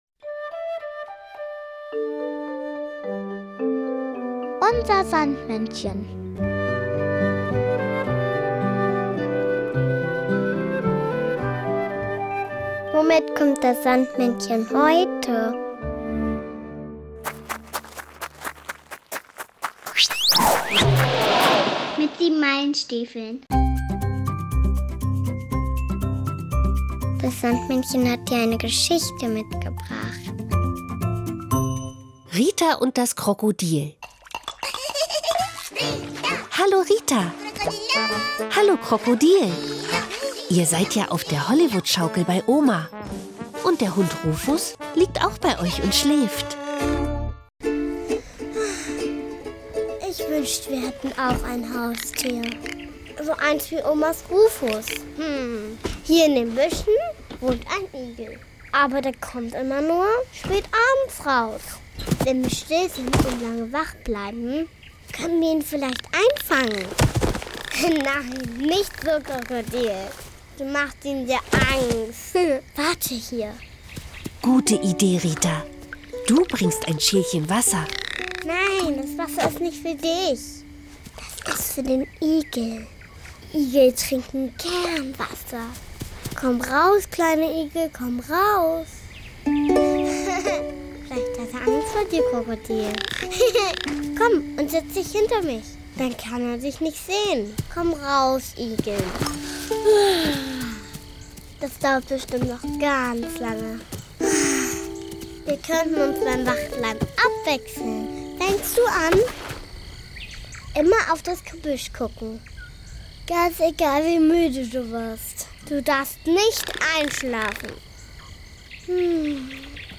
Das Sandmännchen hat dir aber nicht nur diese Geschichte mitgebracht, sondern auch noch das Kinderlied "Wenn sich Igel küssen" aus dem großen Volksliederschatz.